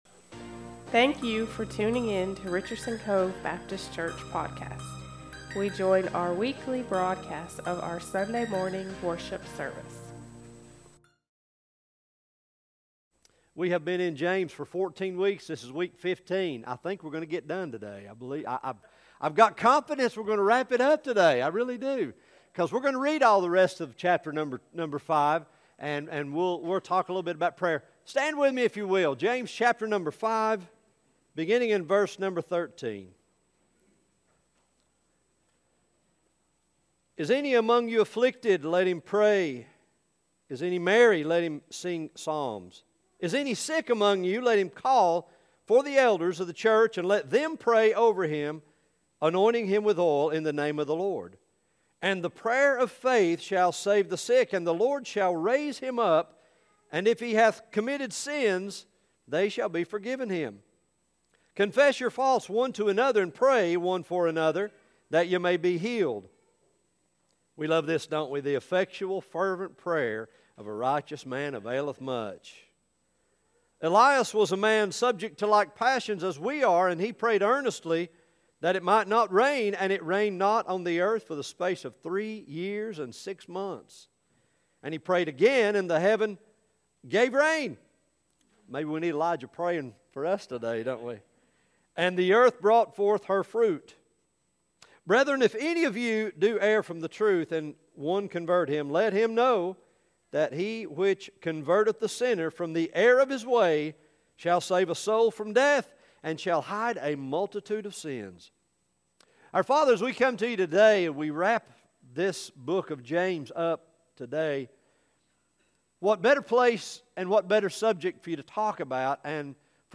Sermons | Richardson's Cove Baptist Church